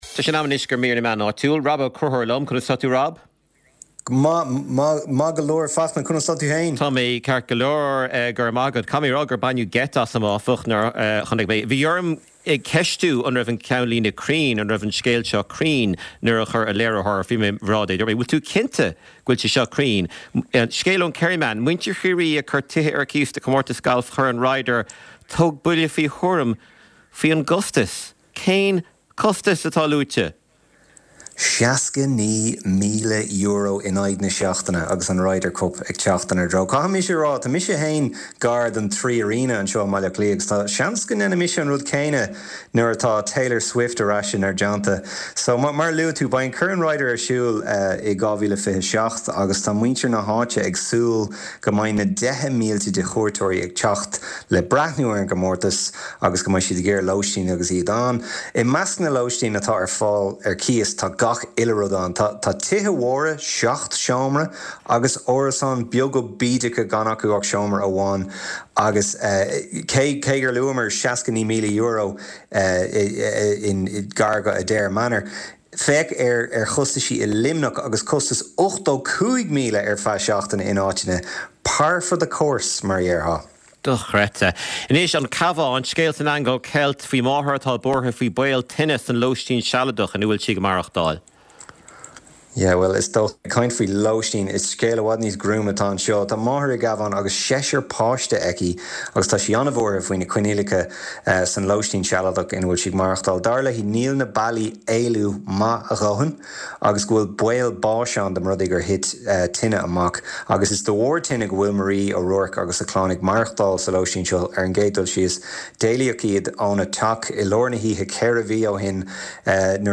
Clár cúrsaí reatha an tráthnóna á chur i láthair ón Lárionad Raidió i mBaile Átha Cliath. Scéalta náisiúnta agus idirnáisiúnta a bhíonn faoi chaibidil ar an gclár, le plé, anailís agus tuairiscí.